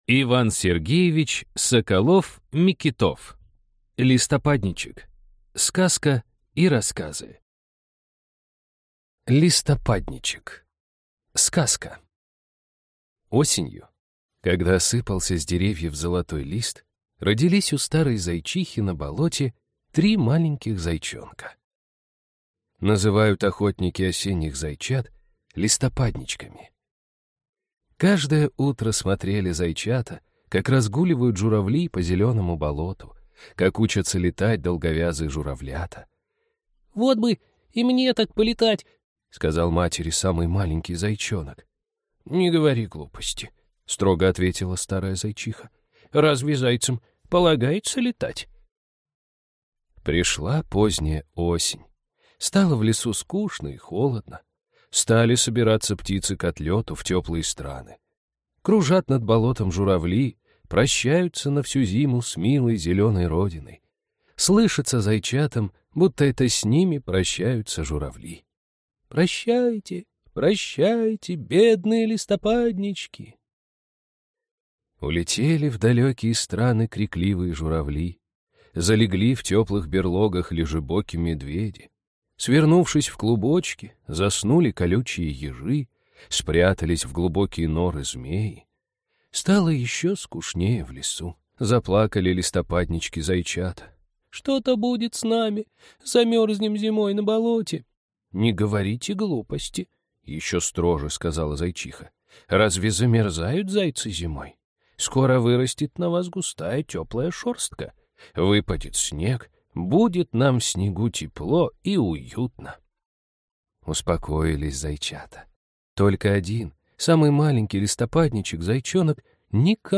Аудиокнига Листопадничек | Библиотека аудиокниг